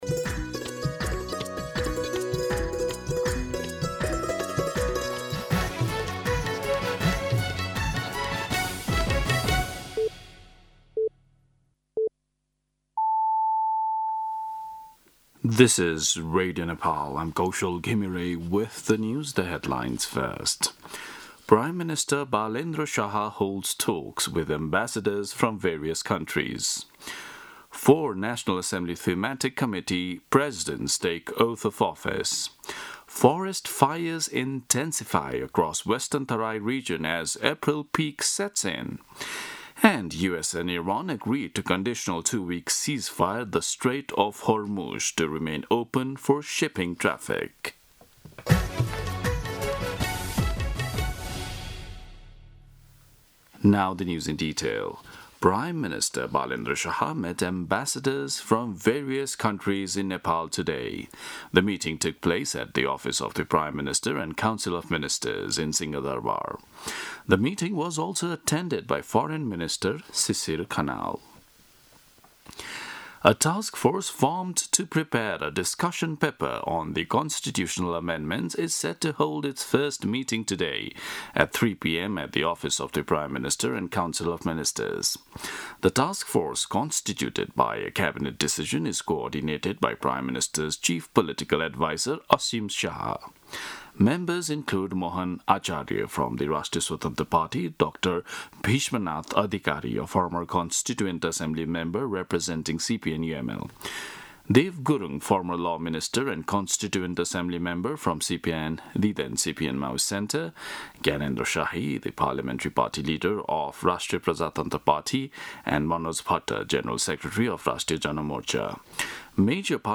दिउँसो २ बजेको अङ्ग्रेजी समाचार : २५ चैत , २०८२
2-pm-English-News-12-25.mp3